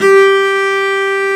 Index of /90_sSampleCDs/Roland - String Master Series/STR_Cb Bowed/STR_Cb3 Arco nv
STR CELLO 07.wav